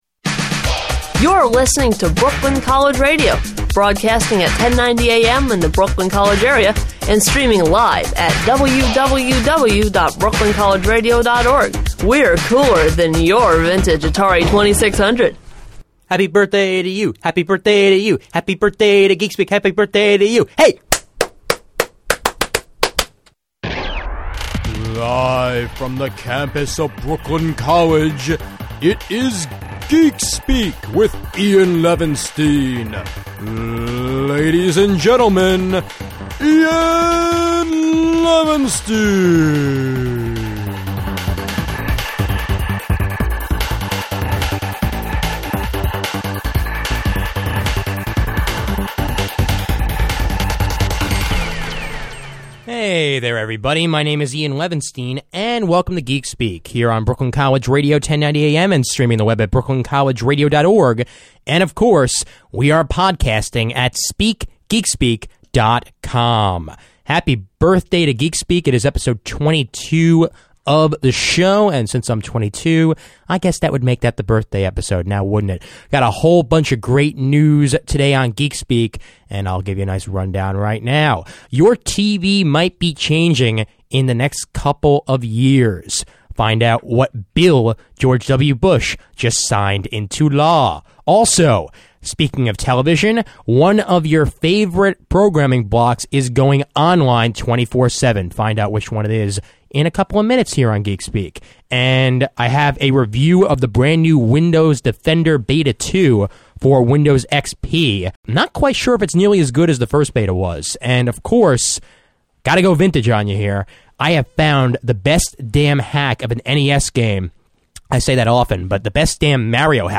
As you will hear, I added a message before the episode that is a cry for help for people who know Skype and Gizmo.
This episode of Geekspeak was recorded on Thursday, February 23rd, 2006 at the Brooklyn College Radio Studio.